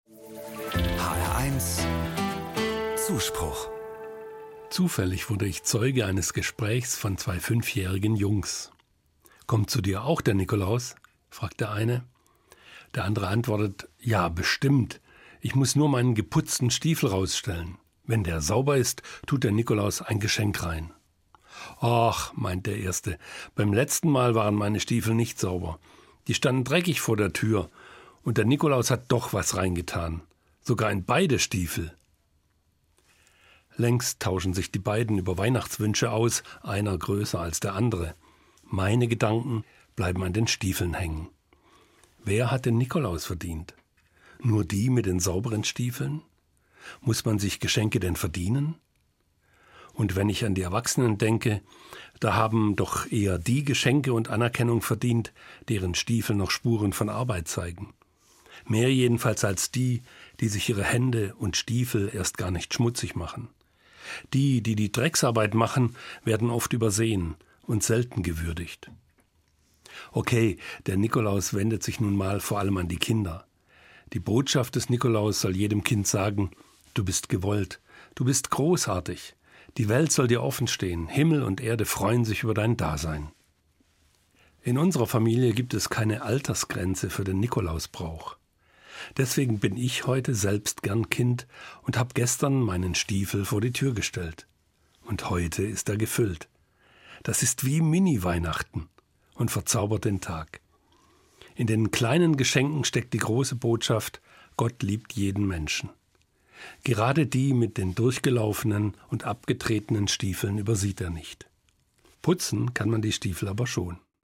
Evangelischer Pfarrer, Frankfurt